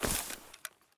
dc0f4c9042 Divergent / mods / Soundscape Overhaul / gamedata / sounds / material / human / step / grass4.ogg 33 KiB (Stored with Git LFS) Raw History
grass4.ogg